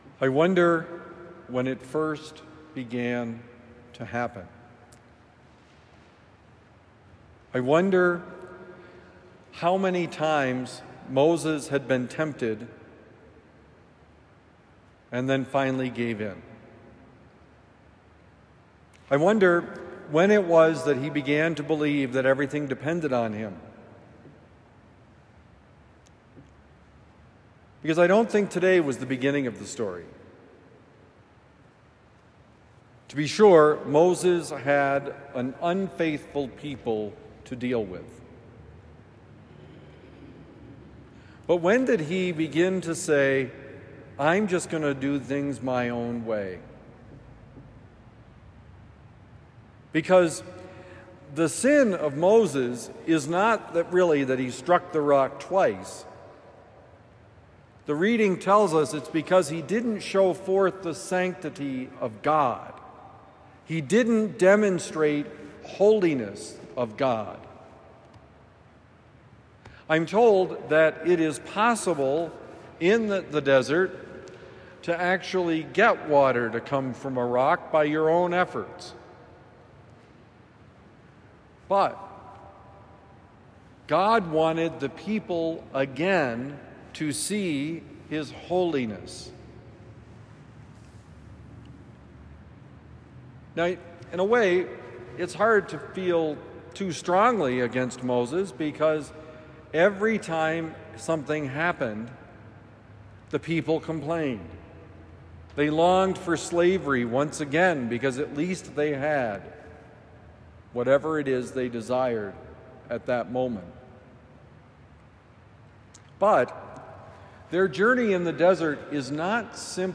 Not all at once: Homily for Thursday, August 7, 2025